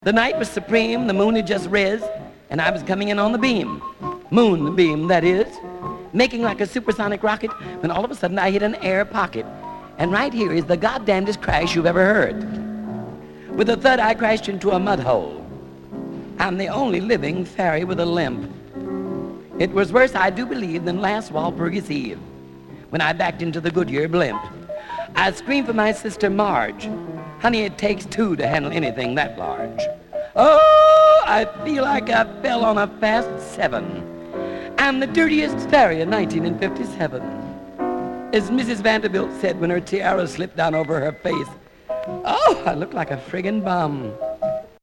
were recorded in New Orleans